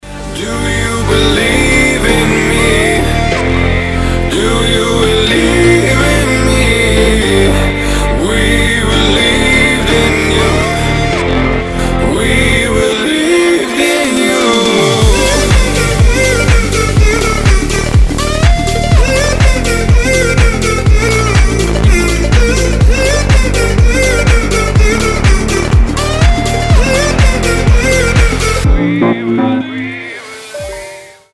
позитивные
громкие
красивый мужской голос
мотивирующие
dance
Electronic
EDM
future house
Bass
house